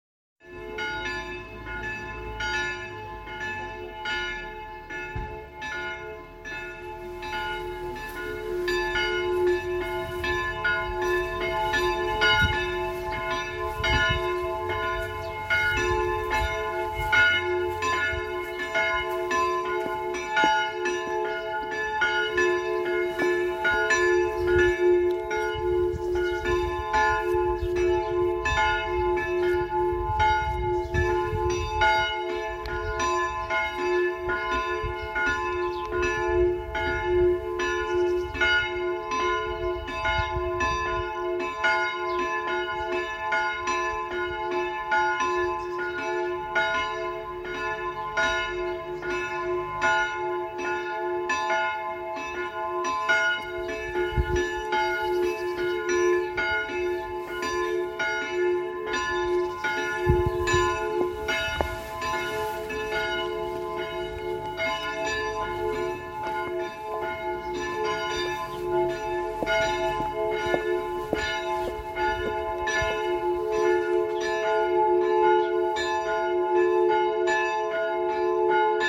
Glocken_Vetzberg.mp3